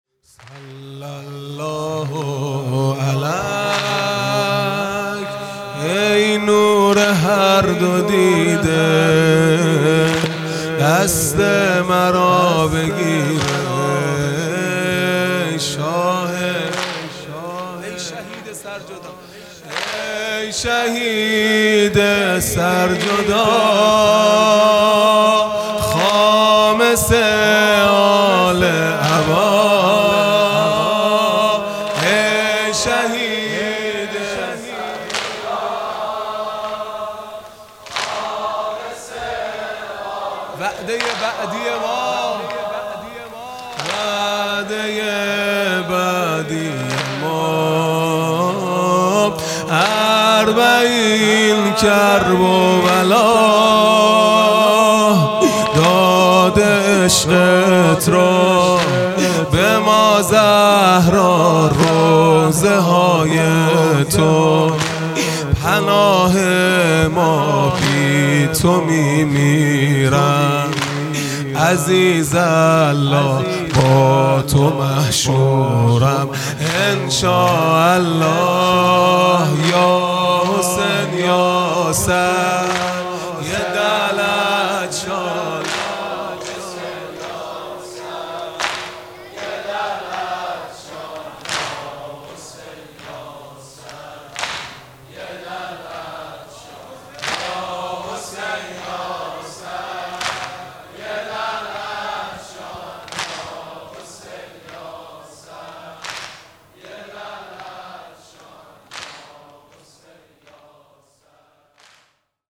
خیمه گاه - هیئت بچه های فاطمه (س) - واحد | صل الله علیک ای نور هر دو دیده | 31 تیرماه 1402